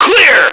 Index of /action/sound/radio/male